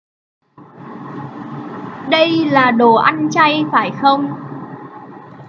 實用越語教學
越語發音教學由非凡教育中心提供